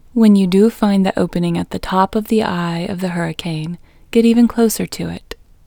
IN – the Second Way – English Female 10